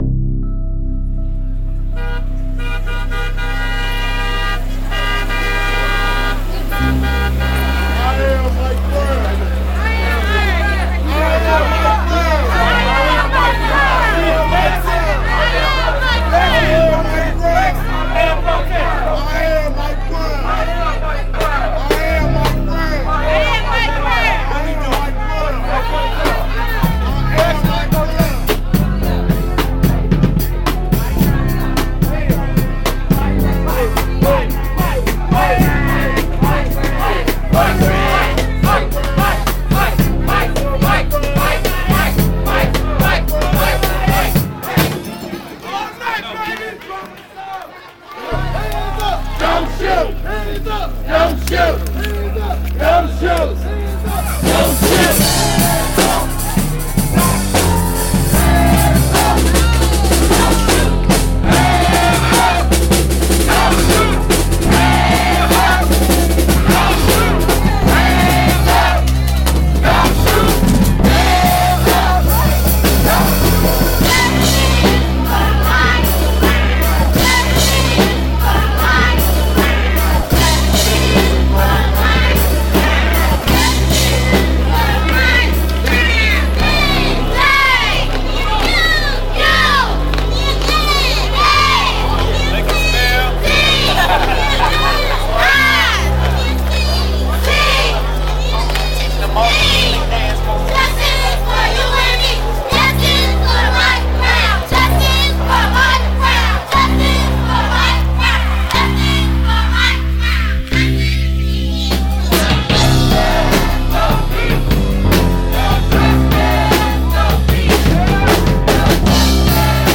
All sounds recorded at protest in Ferguson, MO over Mike Brown shooting.